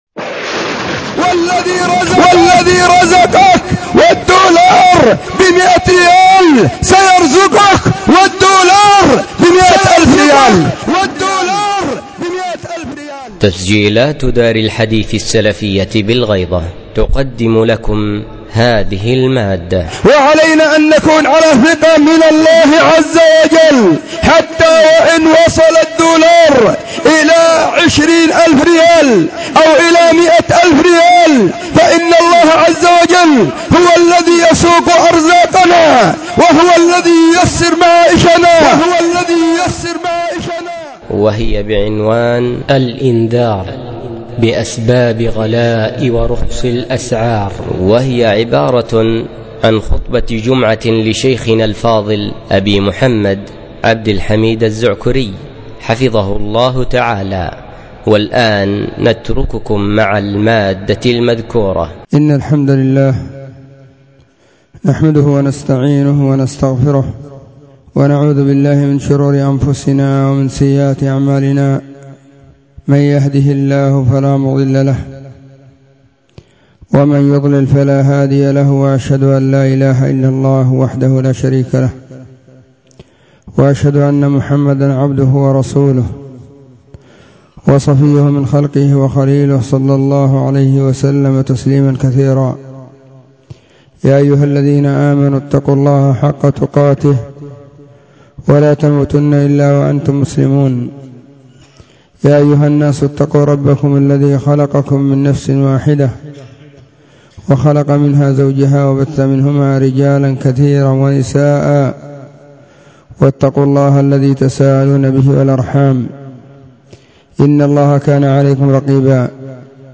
🎙فهذه خطبة جمعة بعنوان :*⚠الإنذار بأسباب غلاء ورخص الأسعار⚠*
📢 وكانت – في – مسجد – الصحابة – بالغيضة – محافظة – المهرة – اليمن.
الجمعة 28 ربيع الثاني 1443 هــــ | الخطب المنبرية | شارك بتعليقك